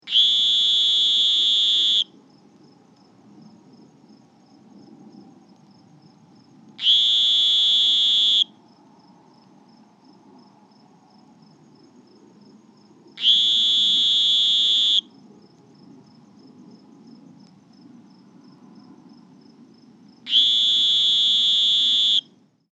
Advertisement Calls
It is produced by a male frog in order to attract females during the breeding season and to warn other rival males of his presence.
Sound  This is a 22 second recording of a short series of advertisement calls of a male Sonoran Green Toad calling at night from dry ground several feet above a flooded ditch in Pima County Arizona.